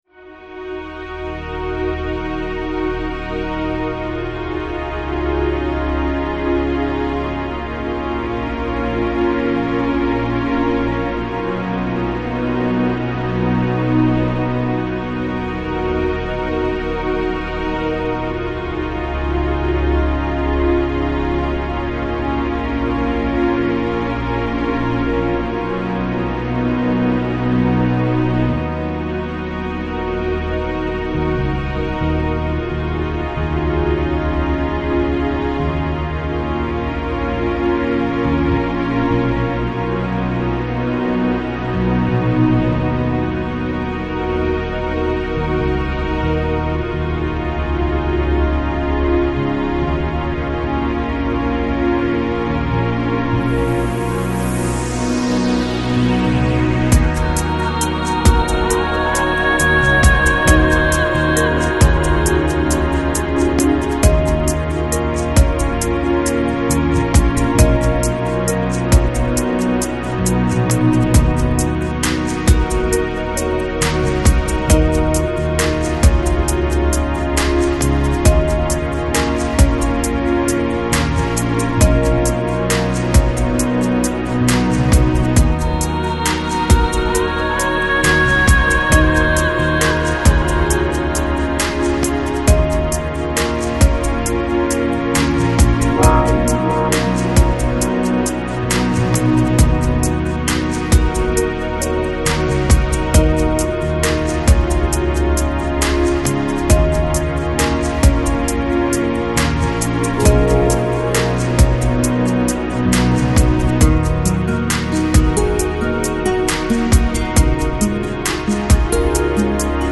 Lo-Fi, Lounge, Chillout